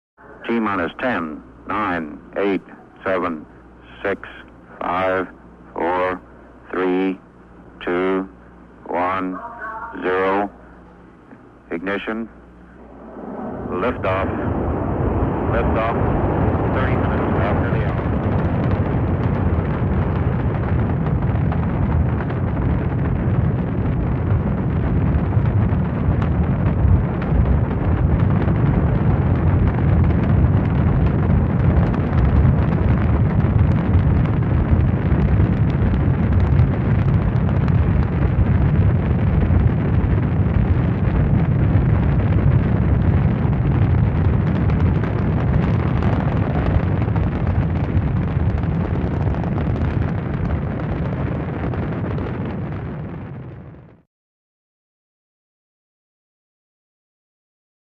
Launch | Sneak On The Lot
Spacecraft Launch With Futzed Countdown, Then Roaring Lift Off.